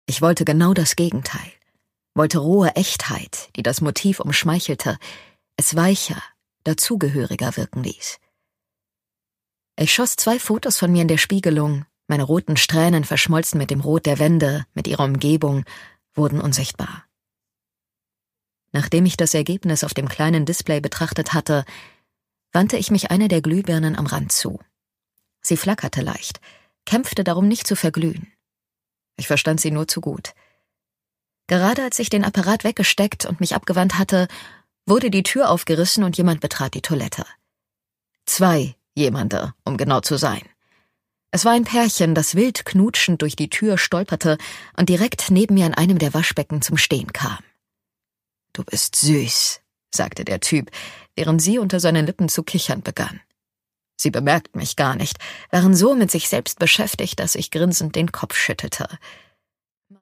Sara West: Blooming Trust - Rose Garden, Band 2 (Ungekürzte Lesung)
Produkttyp: Hörbuch-Download